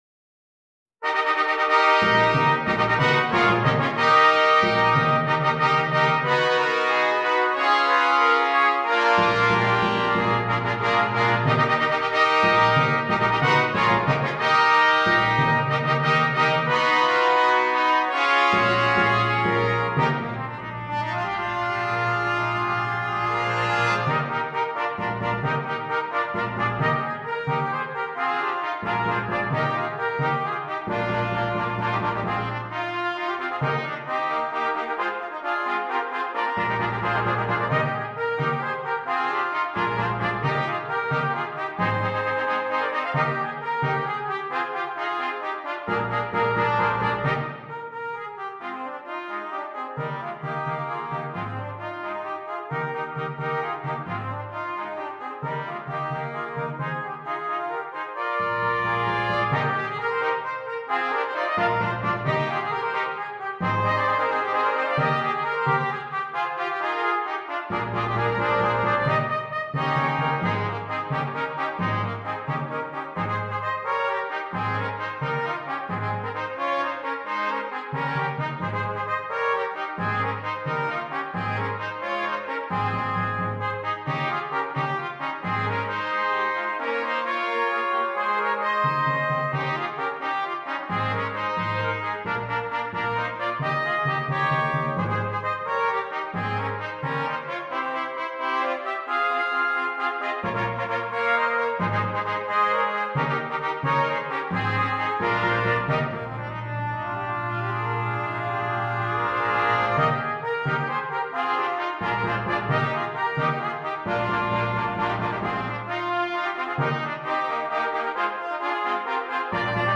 5 Trumpets and Timpani